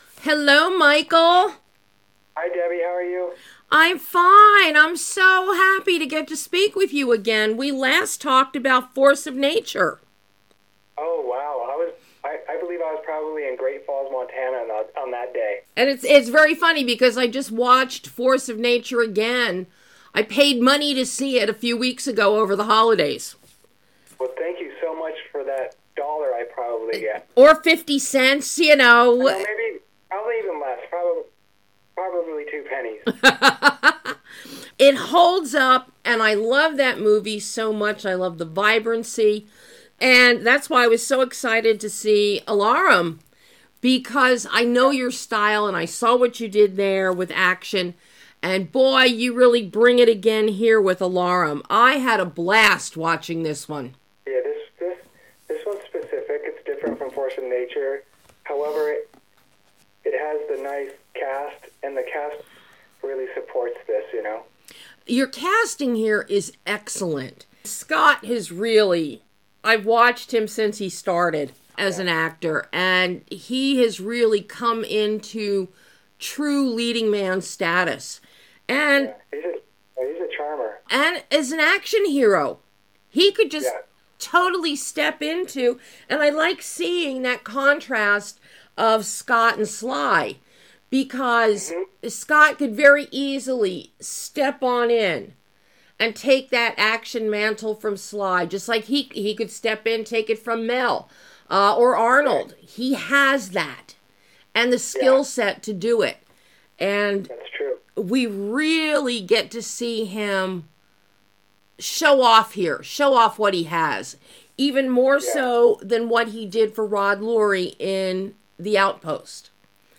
An exclusive interview with director MICHAEL POLISH discussing his latest action-packed film ALARUM starring Scott Eastwood and Sylvester Stallone.